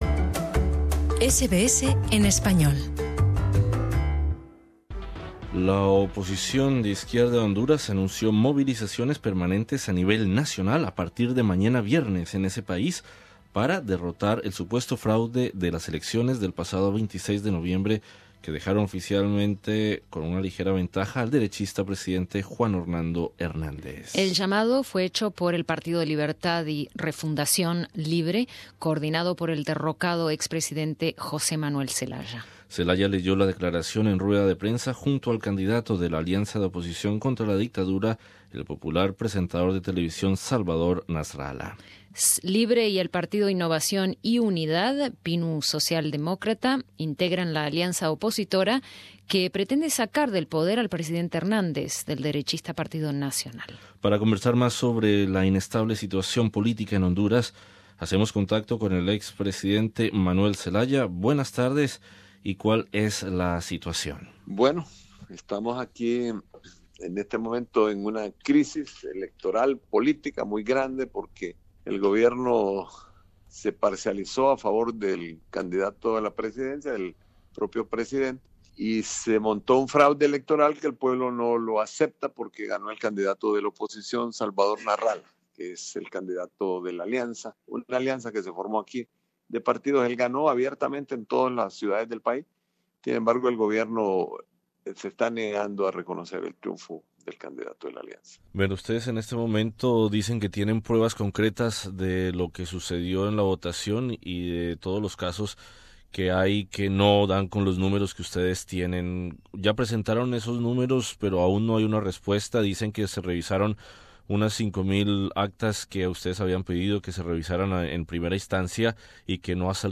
Entrevista con el ex mandatario hondureño Jose Manuel Zelaya.